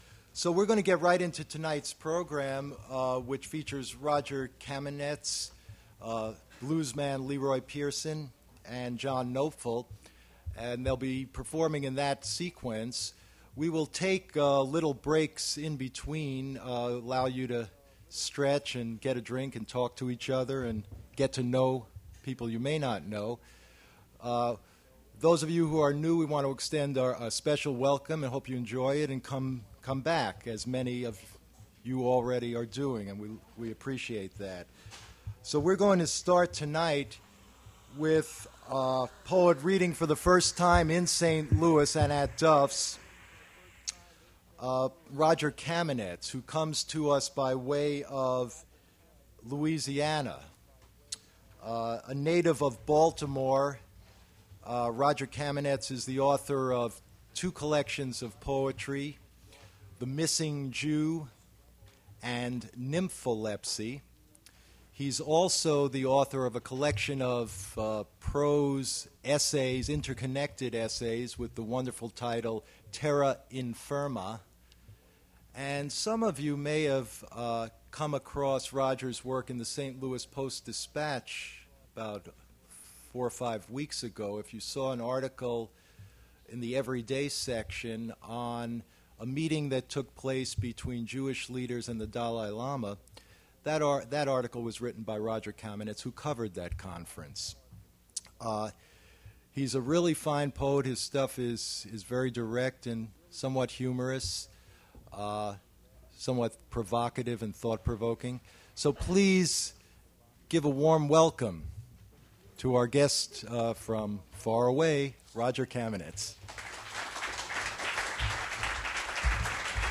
Poetry reading
mp3 edited access file was created from unedited access file which was sourced from preservation WAV file that was generated from original audio cassette. Language English Identifier CASS.727 Series River Styx at Duff's River Styx Archive (MSS127), 1973-2001 Note Cut beginning of the introduction - superfluous material.